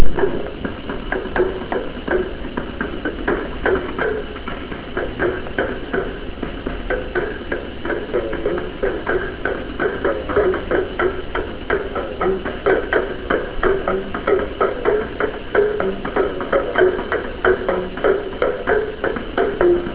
Drum1.aiff